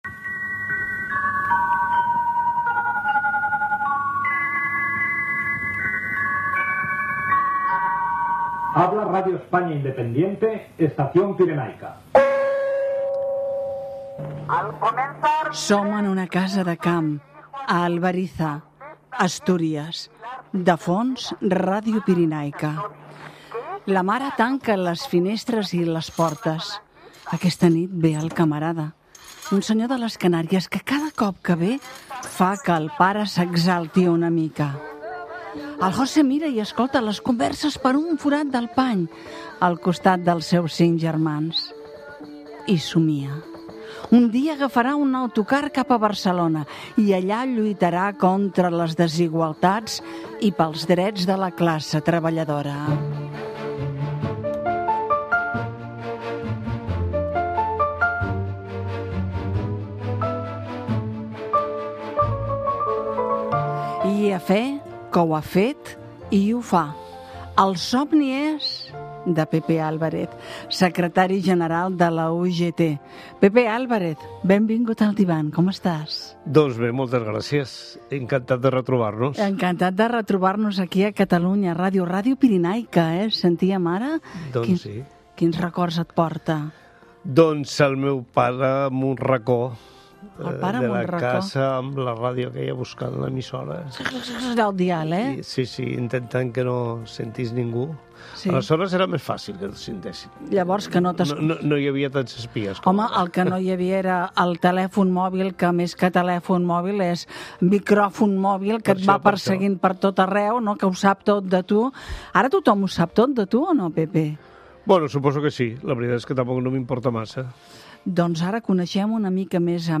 "El divan", un espai on els convidats es deixen anar. Illusions, desitjos, records i msiques que acompanyen les converses ms sinceres, ms ntimes.